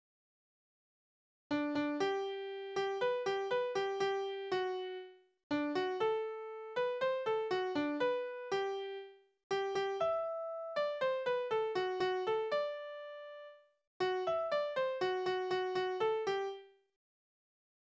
Balladen: Der junge Bräutigam und seine frühere Liebste
Tonart: G-Dur
Taktart: C (4/4)
Tonumfang: große None
Besetzung: vokal